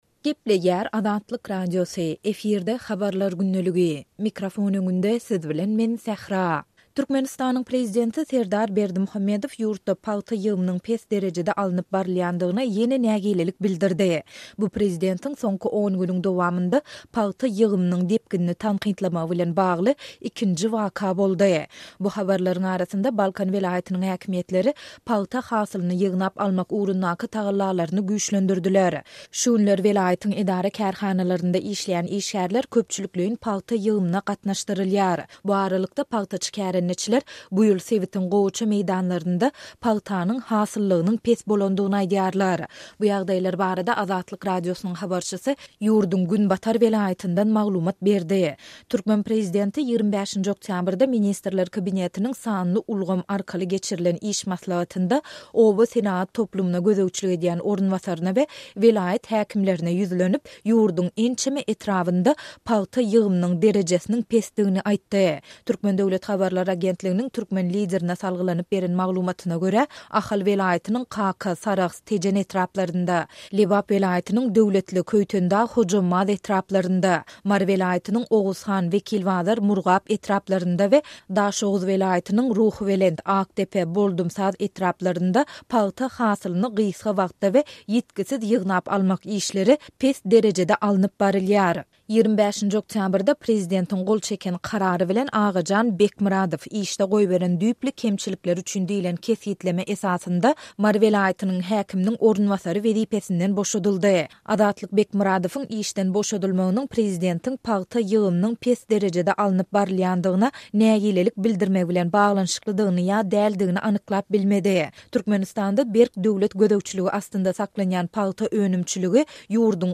Bu ýagdaýlar barada Azatlyk Radiosynyň habarçysy ýurduň günbatar welaýatyndan maglumat berdi.